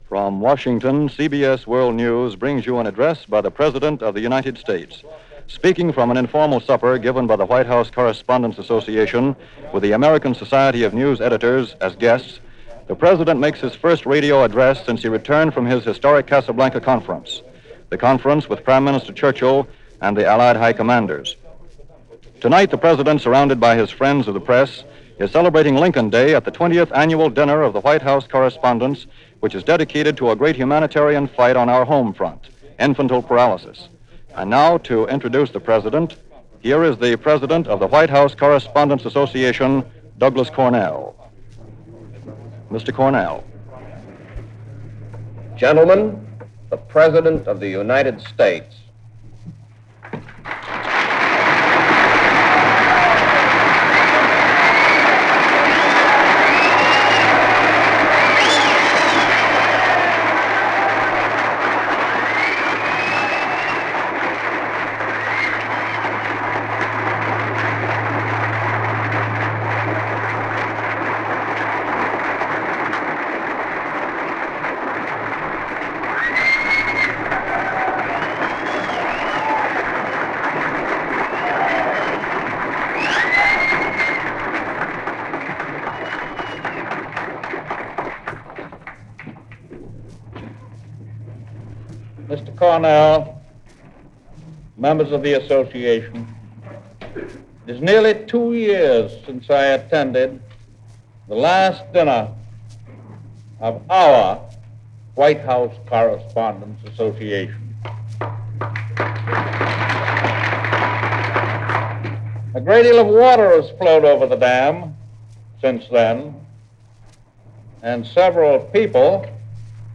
February 12, 1943 - President Roosevelt Addresses The White House Correspondents Dinner - Past Daily Reference Room
FDR-White-House-Correspondents-Dinner-1943.mp3